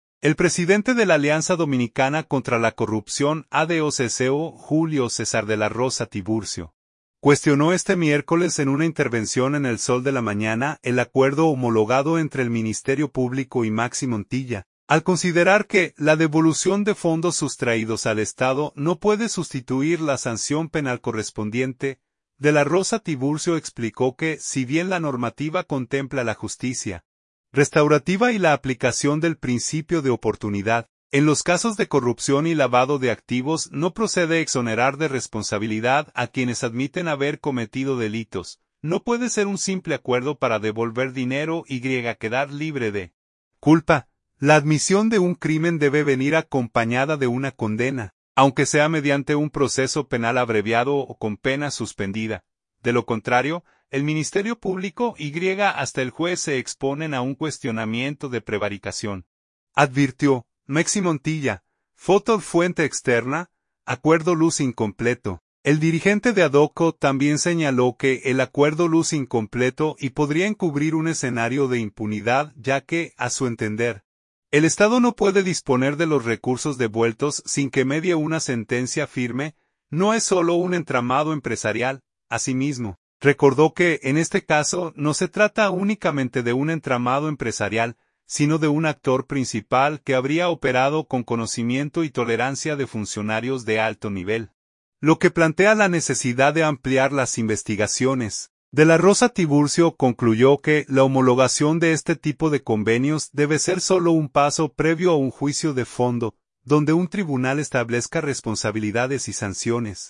una intervención en El Sol de la Mañana